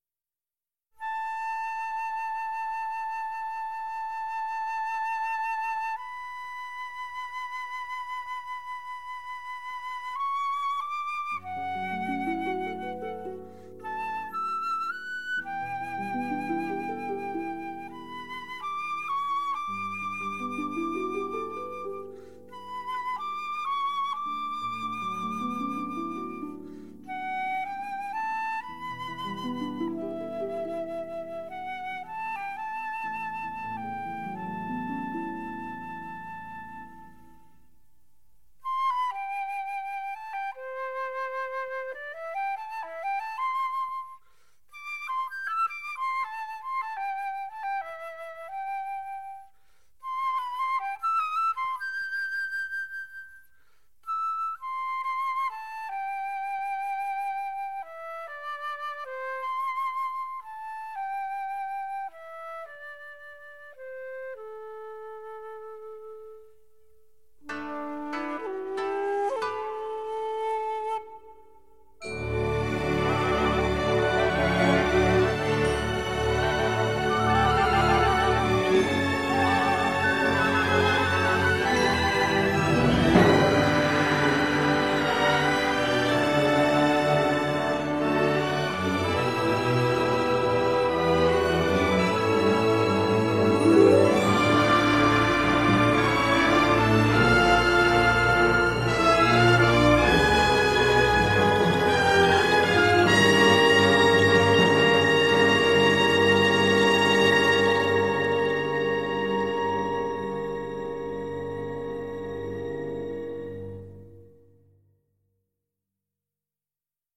Une musique d’aventure comme on n’en fait plus